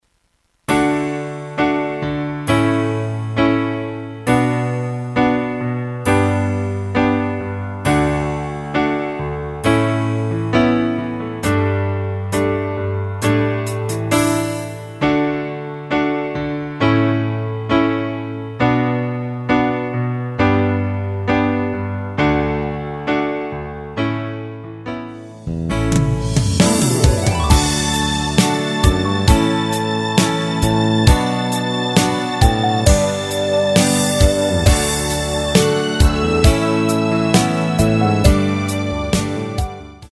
전주없이 시작하는 곡이라 전주 4마디 만들어 놓았습니다. 하이햇 소리 끝나고 노래 들어가시면 됩니다.